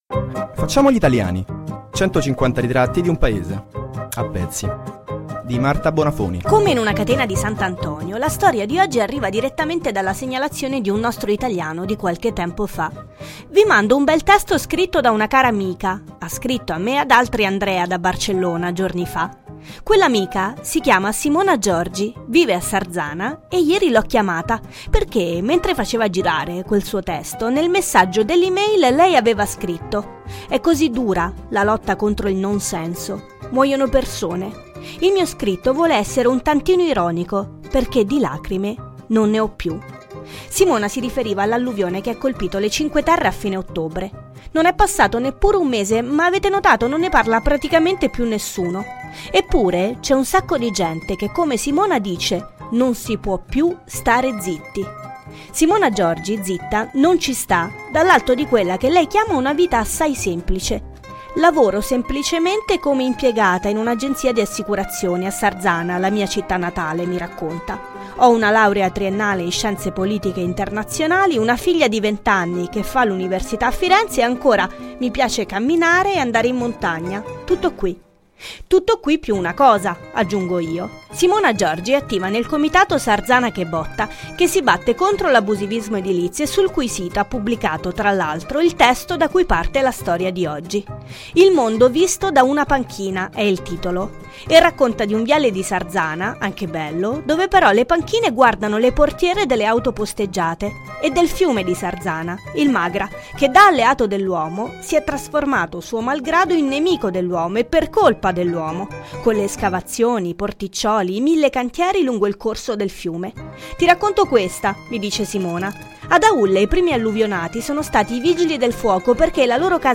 Intervista
Nell’ambito della trasmissione radiofonica “Facciamo gli italiani! 150 ritratti di un Paese a pezzi” è stata intervistata